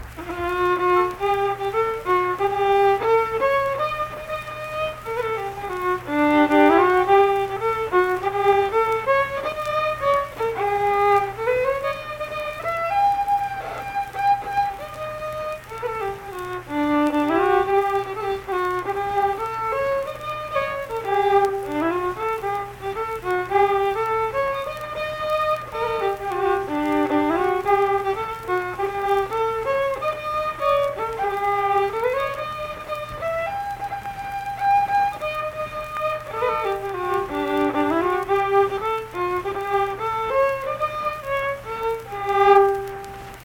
Unaccompanied vocal and fiddle music
Instrumental Music
Fiddle